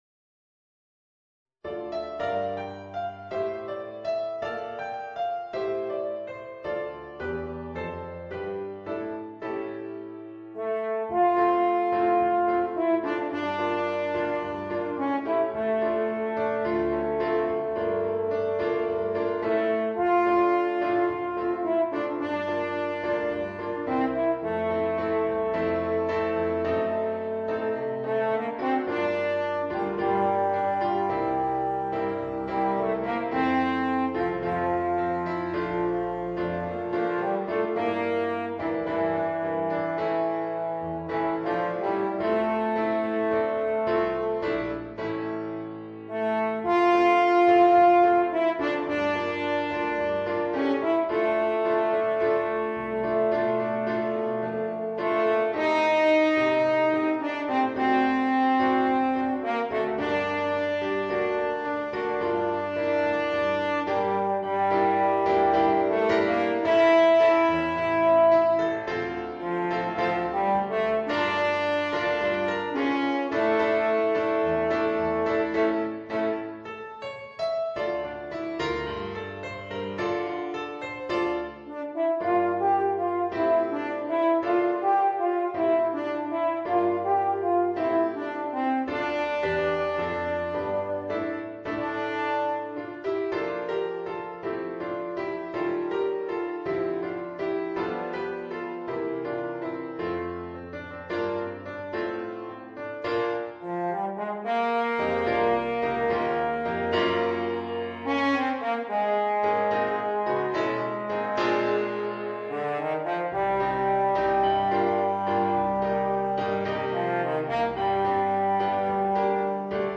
Voicing: Eb Horn and Piano